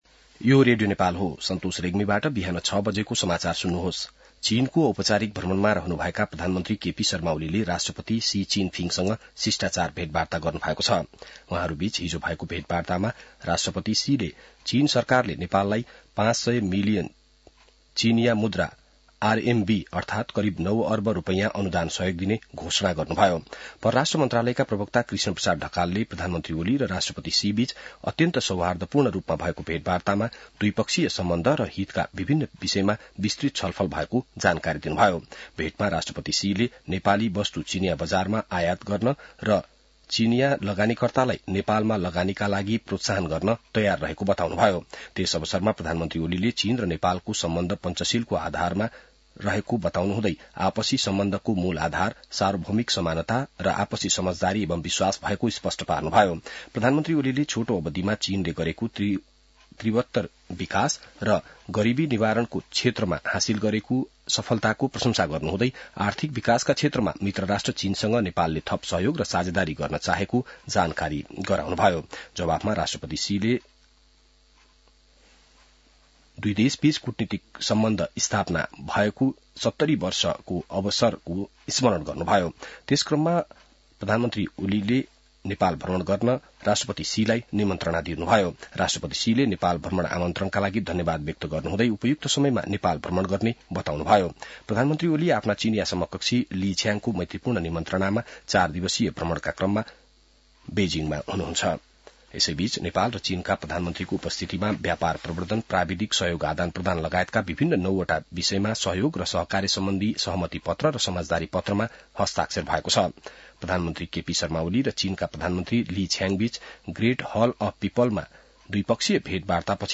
बिहान ६ बजेको नेपाली समाचार : २० मंसिर , २०८१